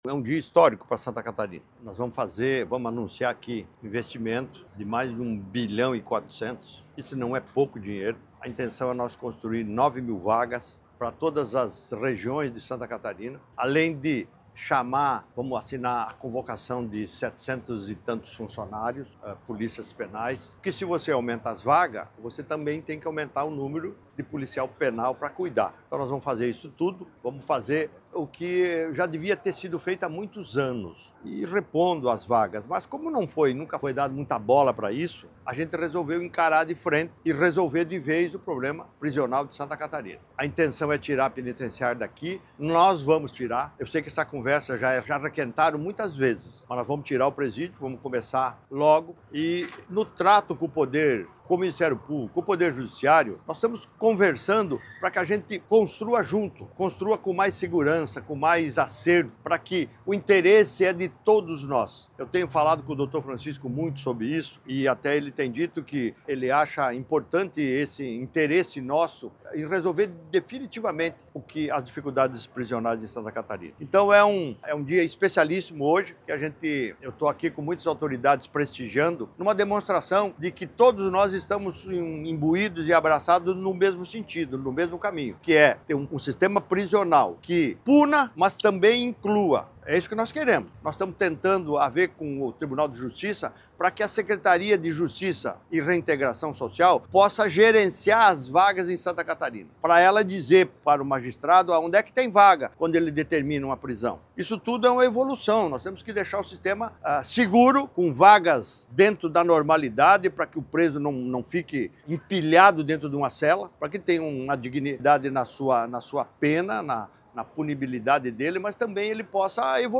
SONORA – Governador anuncia investimento histórico de R$ 1,4 bilhão para ampliação do sistema prisional catarinense
O governador Jorginho Mello destacou a importância desse, que é o maior investimento na história do sistema prisional catarinense:
A Secretária de Estado de Justiça e Reintegração Social, Danielle Amorim Silva, ressalta que o investimento vai ser usado exatamente em cada ponto que o sistema prisional precisa avançar neste momento: